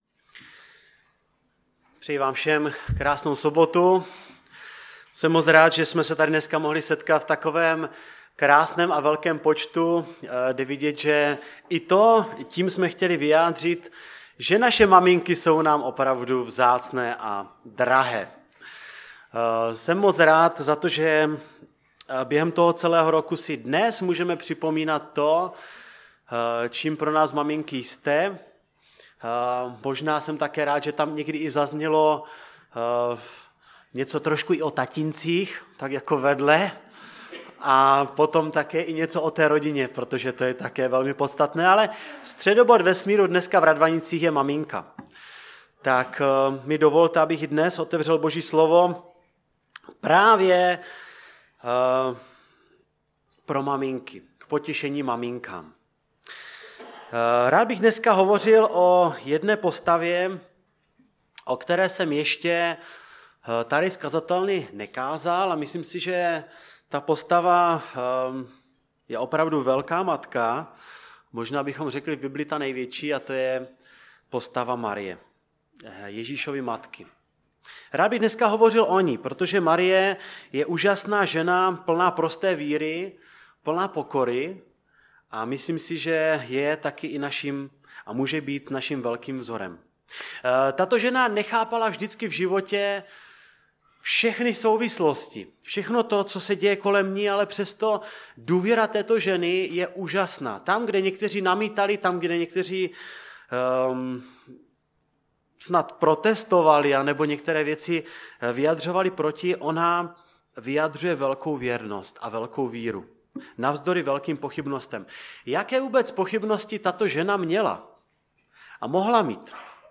Kázání
ve sboře Ostrava-Radvanice (v rámci programu pro maminky).